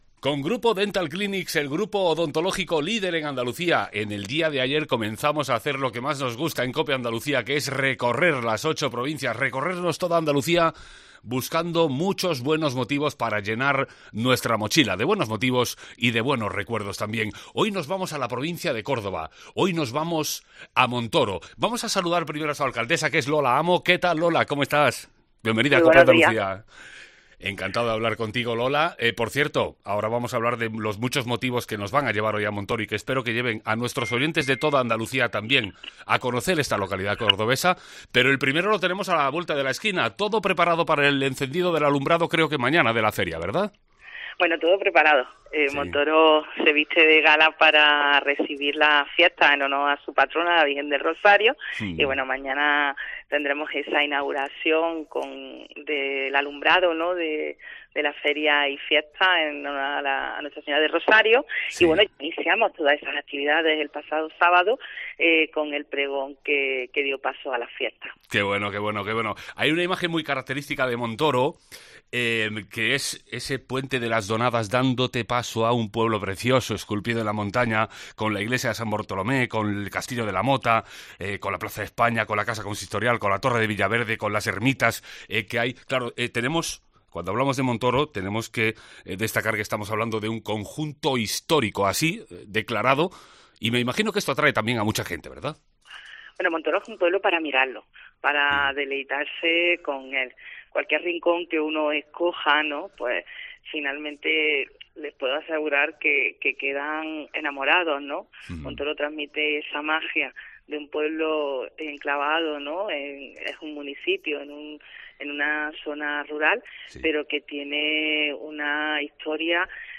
En COPE estamos recorriendo muchos rincones de Andalucía y lo hacemos con nuestra mochila a cuestas. Hoy "Andalucía en tu Mochila" se ha acercado hasta Montoro, donde su alcaldesa, Lola Amo, nos ha abierto sus puertas para descubrir qué esconde esta localidad de la provincia de Córdoba.